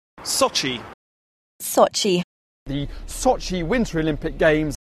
British TV people covering the Sochi Winter Olympics are generally pronouncing Sochi as a rhyme with blotchy:
For one thing, those speakers are using their short British LOT vowel ɔ in the first syllable.
sochi_bbc.mp3